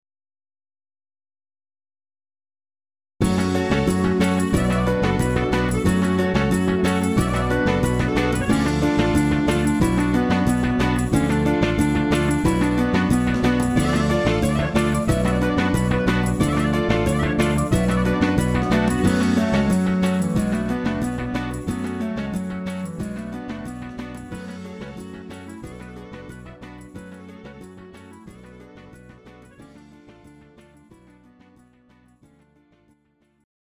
Dimotika